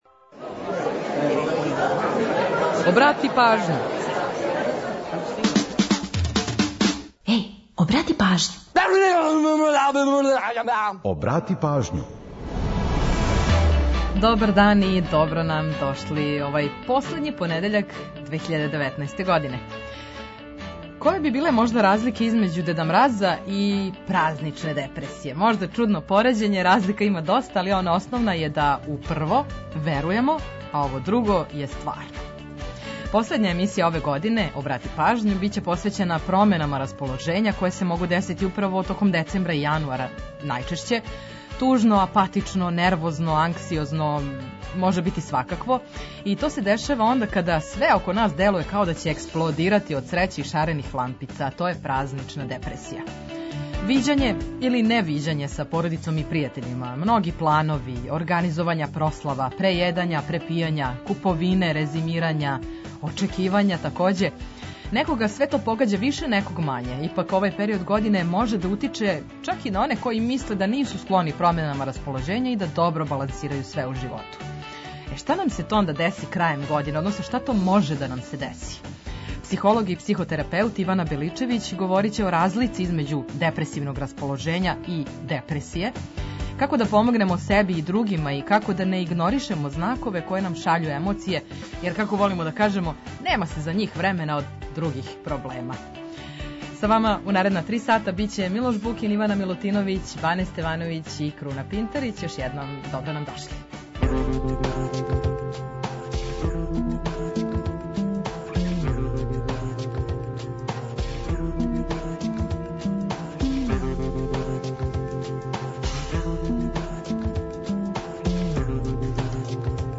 Чућете „Приче о песмама”, пола сата музике из Србије и региона, а упозоравамо и на евентуалне саобраћајне гужве.